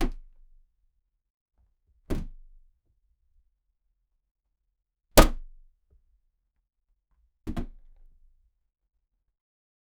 Cabin impacts 0:10 Created Jul 5, 2025 4:28 PM Inside the cabin – chaos.
cabin-impacts-kvhosm2l.wav